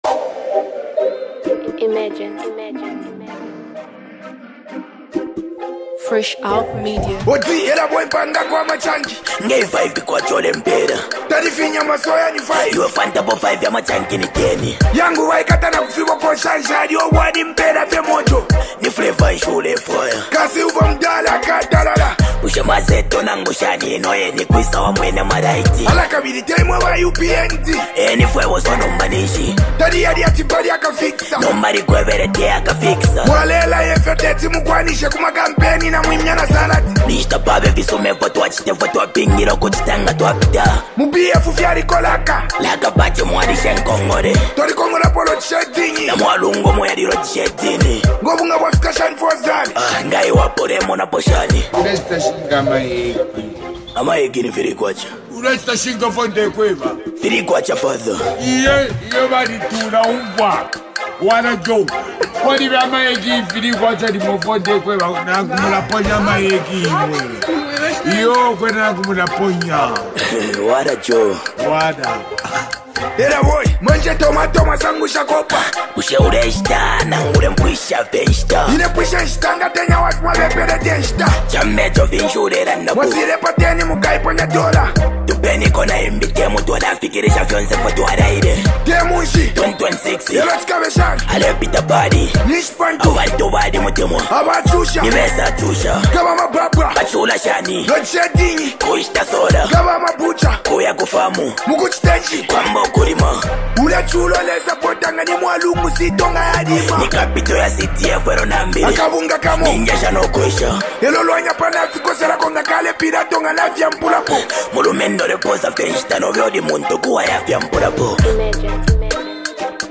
combines sharp lyrics with powerful beats